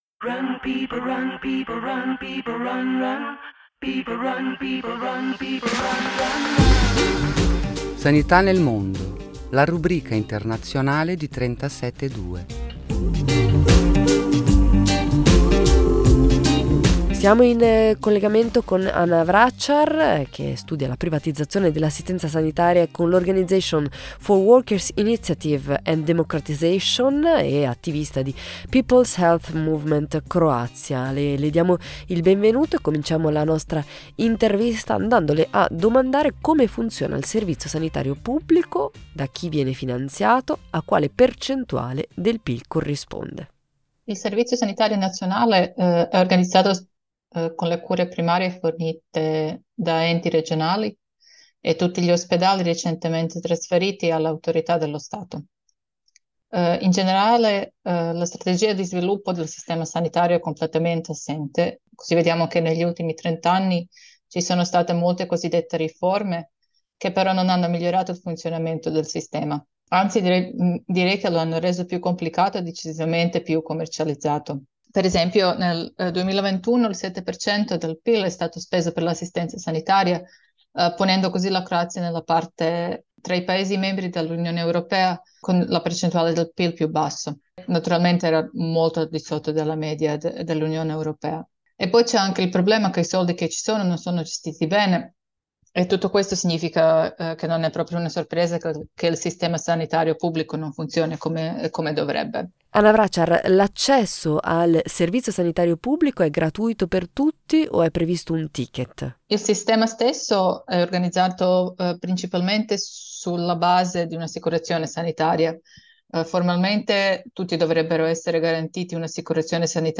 Come sapete, nella rubrica La sanità nel mondo di 37e2 parliamo di altri servizi sanitari nazionali per capire le differenze con il nostro. Questa volta abbiamo parlato di Croazia: ai microfoni con noi